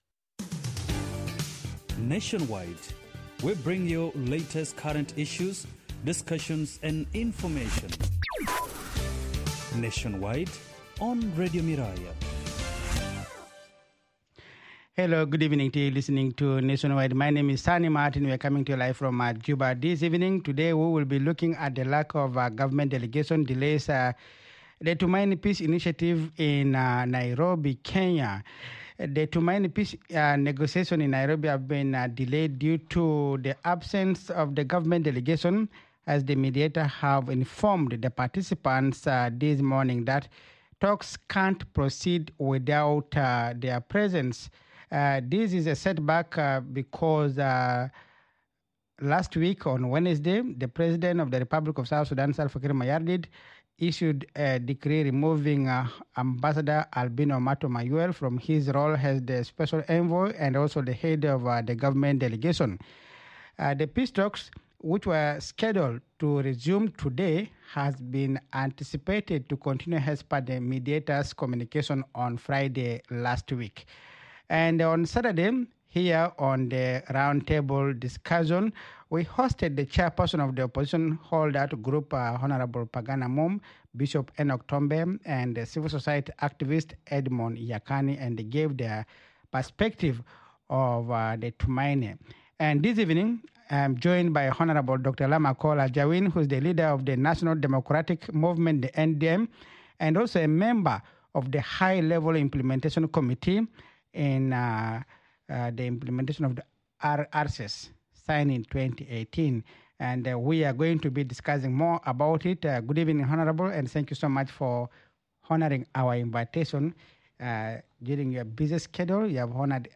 Guest: Dr. Lam Akol Ajawin, the leader of the National Democratic Movement (NDM) and a member of the High-Level Implementation Committee in the RTGoNU